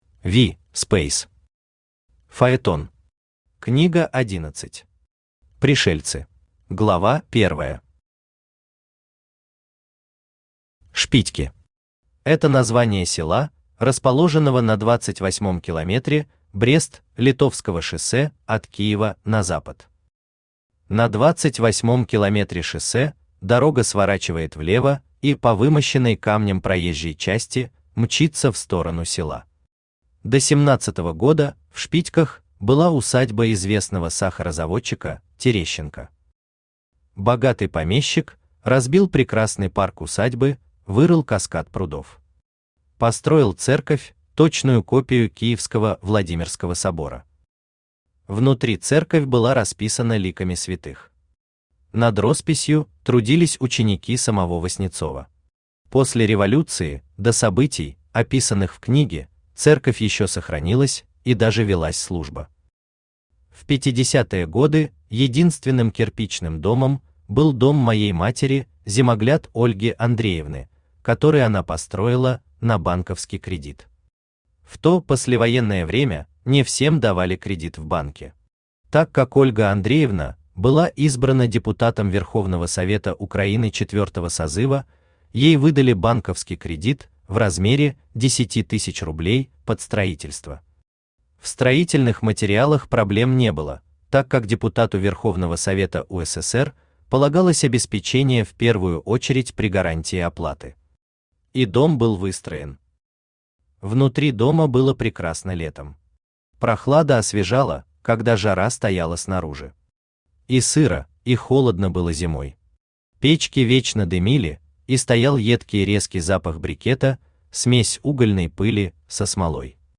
Аудиокнига Фаетон. Книга-11. Пришельцы | Библиотека аудиокниг
Пришельцы Автор V. Speys Читает аудиокнигу Авточтец ЛитРес.